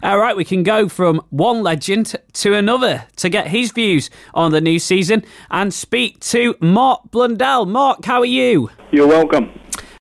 Classic Blooper